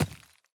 immersive-sounds / sound / footsteps / resources / ore-10.ogg